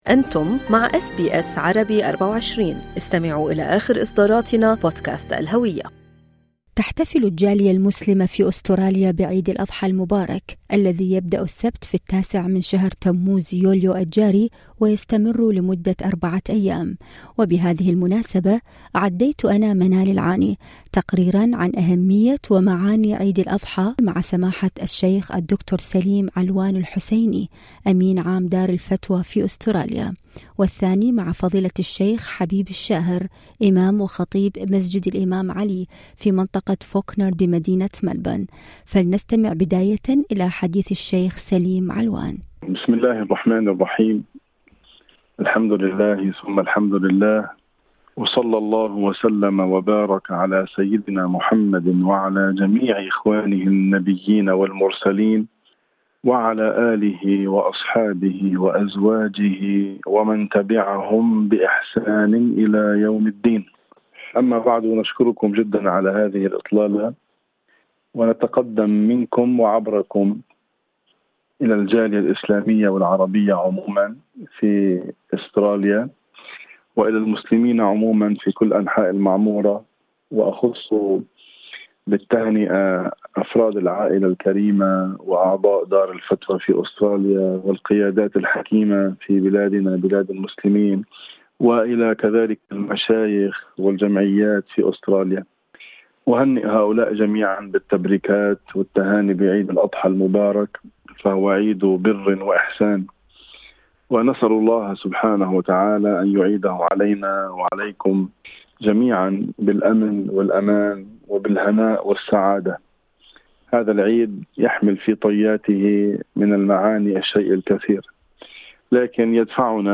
تقريراً عن أهمية ومعاني عيد الأضحى في لقاءات